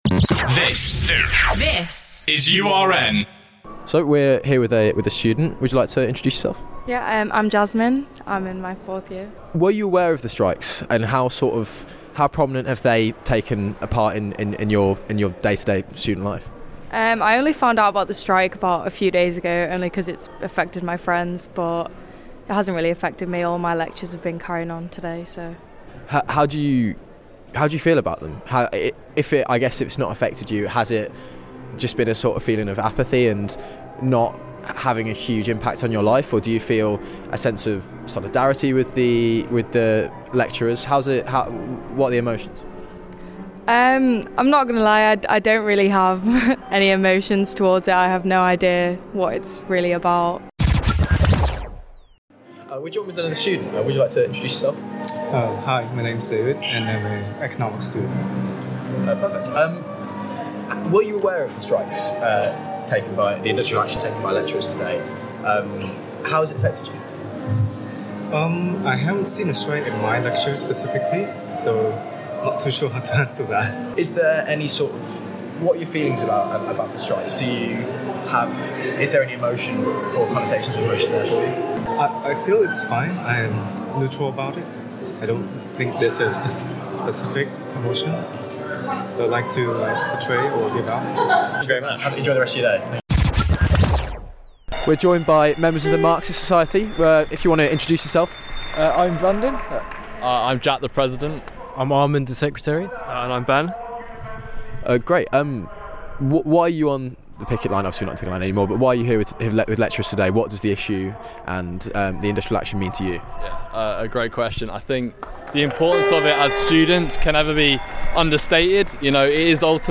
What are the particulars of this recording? In this component of the video-report into the UCU Strikes, URN speak to some students on campus about their views on the industrial action.